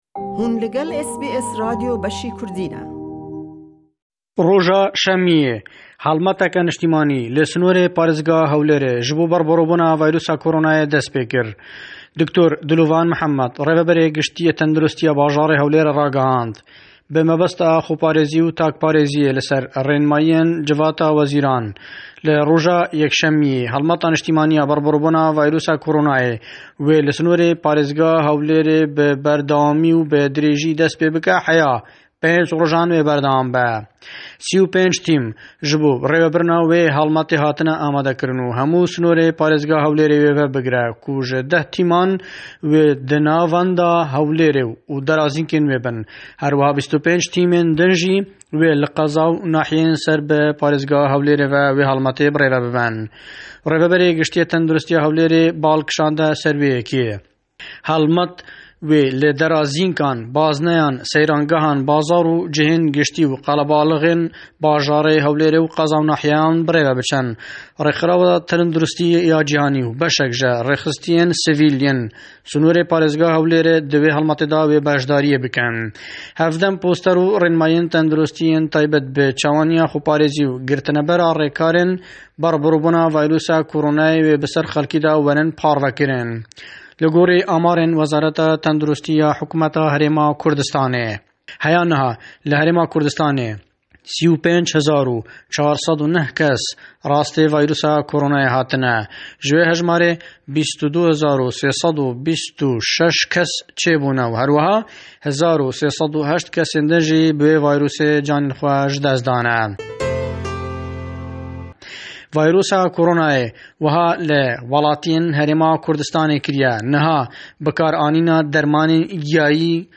Le raportî em hefteye le Hewlêre we, wezaretî tendirustî Herêmî Kurdistan rênimayî tûnd tir tadegeyênin be mebestî kontroll kirdinî billawbûnewey zortirî vîrusî koronaç Herweha, dermanî gîyayî xwazarî zor bûwe be bê hîç bellgeyekî pûxt bo karîgerî dij be vîrusî-korona.